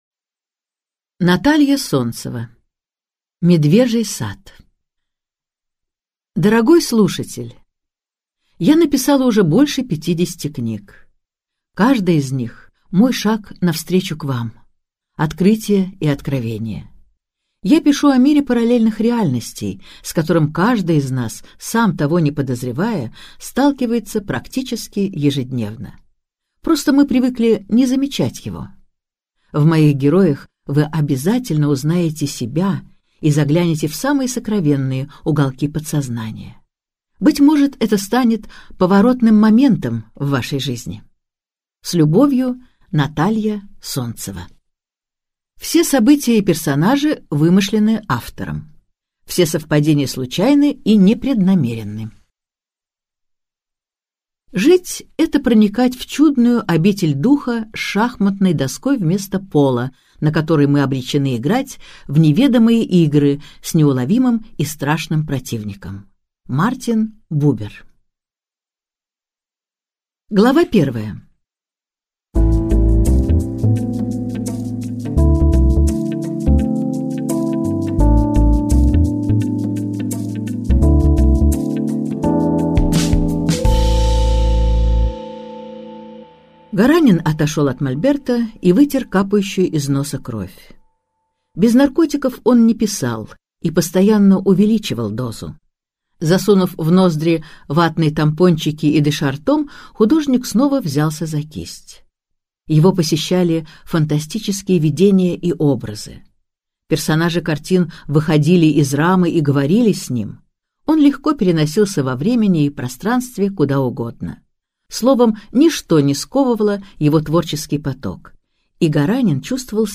Аудиокнига Медвежий сад - купить, скачать и слушать онлайн | КнигоПоиск